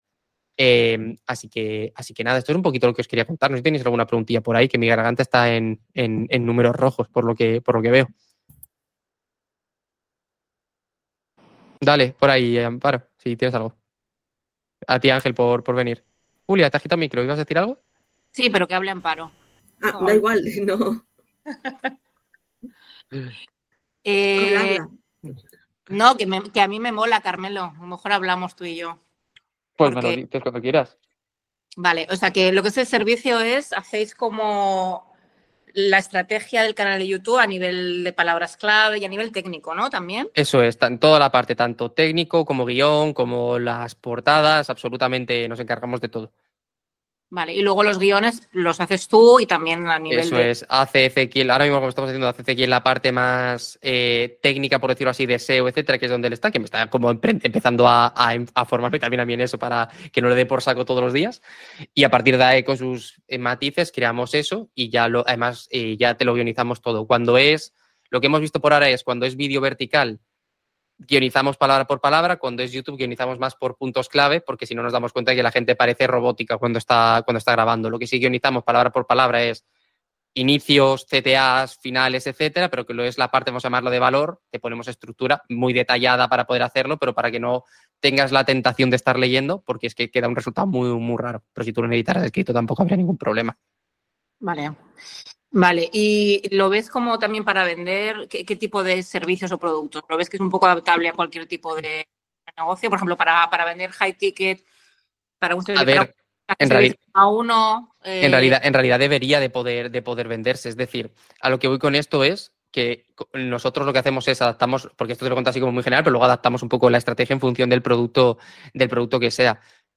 Respondo preguntas de copywriting en directo
Sesión en directo de preguntas y respuestas persuasivas.